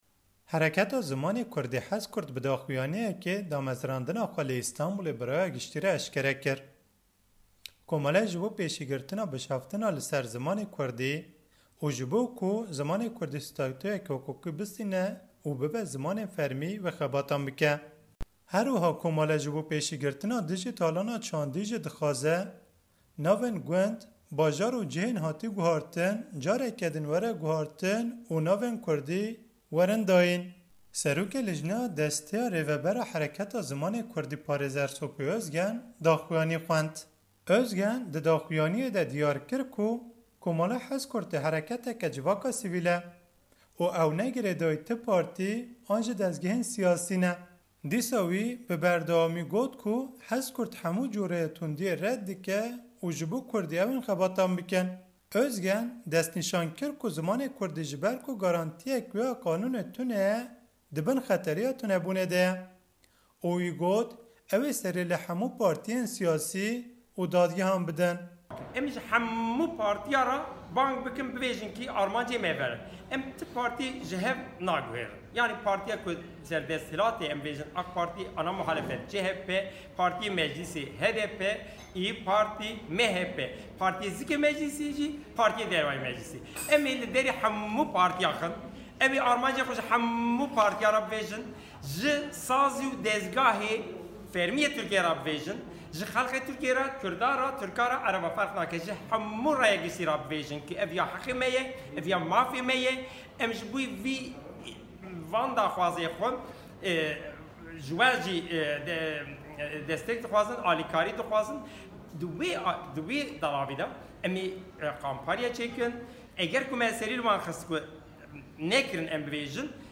Raporta Stenbûl